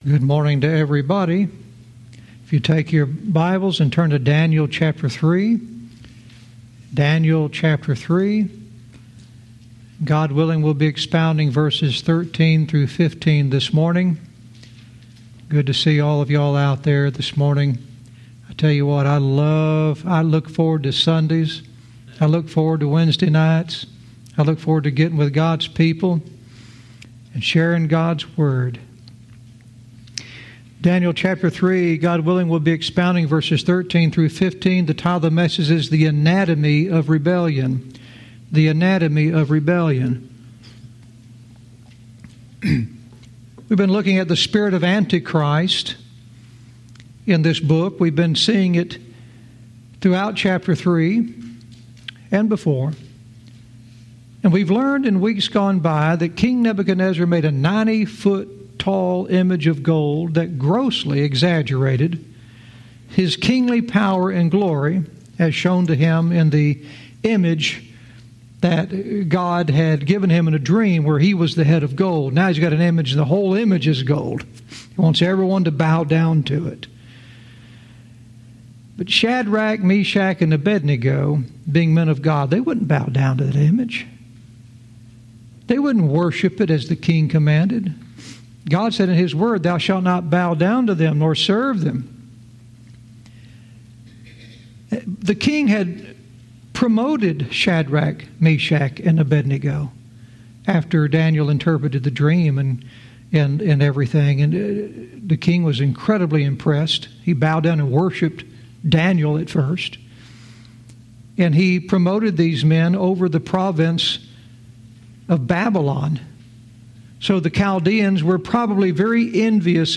Verse by verse teaching - Daniel 3:13-15 "The Anatomy of Rebellion"